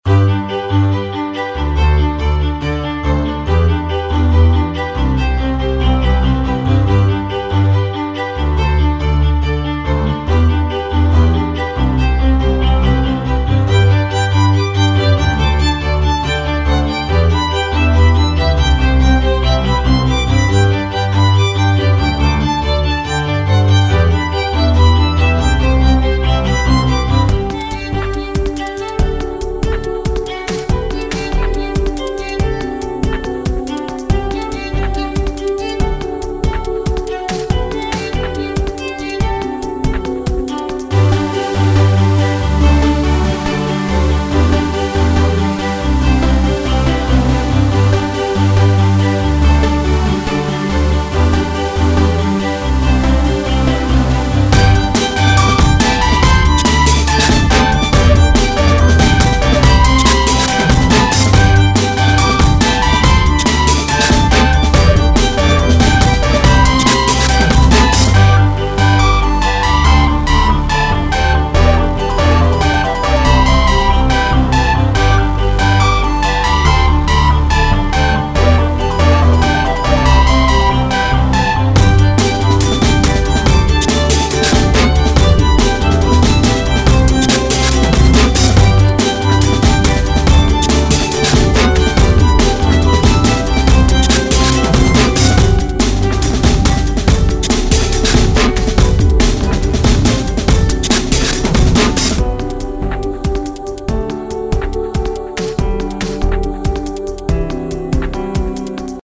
Сведения и мастеринга нет, ибо не разбираюсь в этом:)